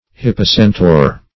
Search Result for " hippocentaur" : The Collaborative International Dictionary of English v.0.48: Hippocentaur \Hip`po*cen"taur\, n. [L. hippocentaurus, Gr.
hippocentaur.mp3